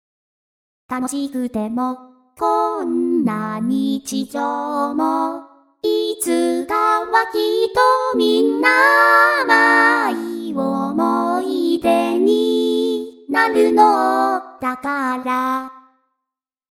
また入浴中に１フレーズだけ思い付きました。多分サビの前半です。
伴奏も何もないけど、珍しく歌詞があるので初音ミクに歌ってもらいました。